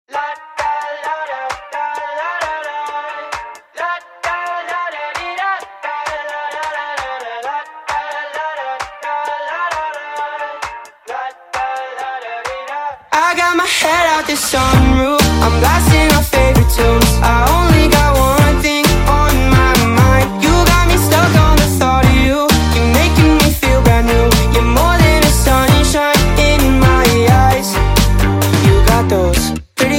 Bright Melody for a Brilliant Summer
With clear vocals